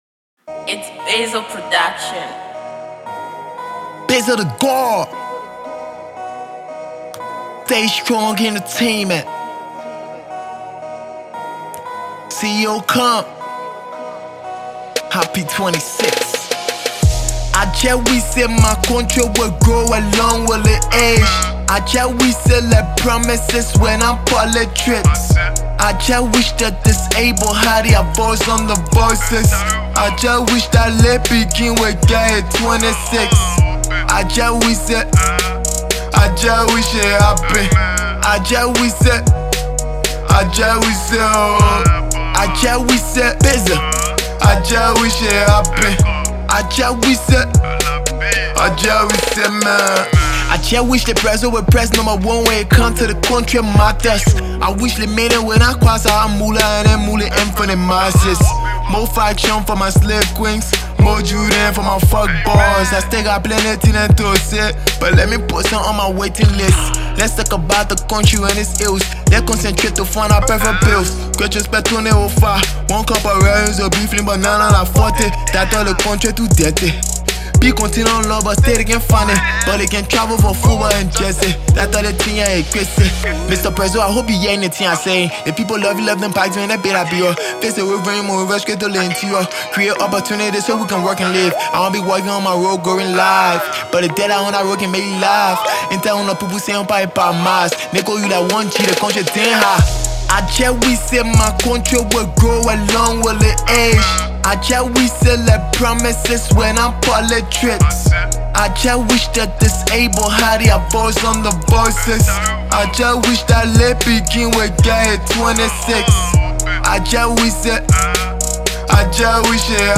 Hip-Hop
hip-hop/Hip-co rapper